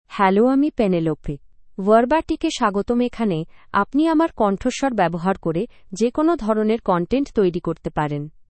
Penelope — Female Bengali AI voice
Penelope is a female AI voice for Bengali (India).
Voice sample
Listen to Penelope's female Bengali voice.
Female
Penelope delivers clear pronunciation with authentic India Bengali intonation, making your content sound professionally produced.